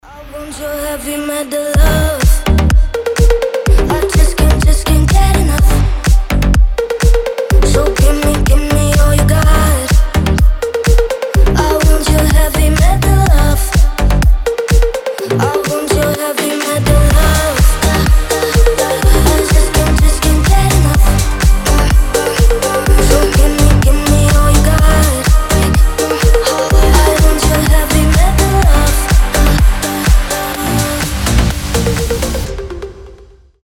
• Качество: 320, Stereo
громкие
EDM
future house
басы
house